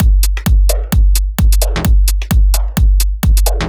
Breathe 130bpm.wav